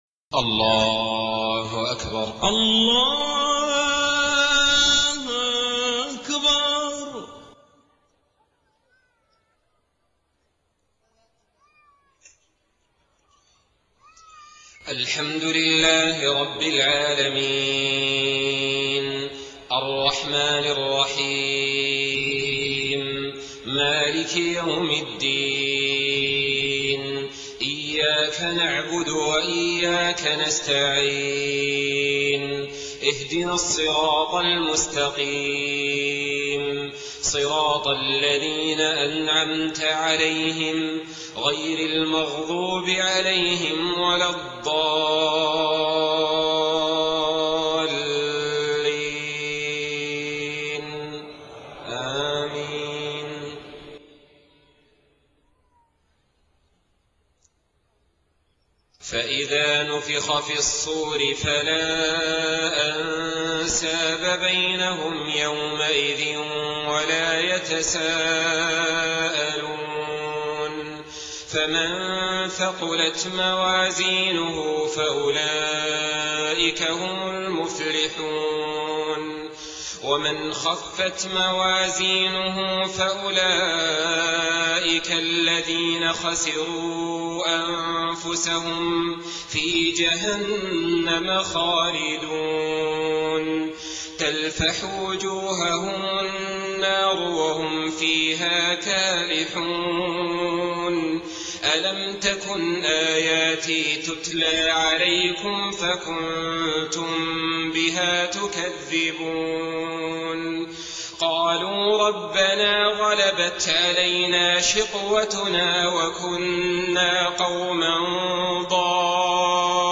صلاة العشاء 5 ربيع الآخر 1429هـ خواتيم سورة المؤمنون 101-118 > 1429 🕋 > الفروض - تلاوات الحرمين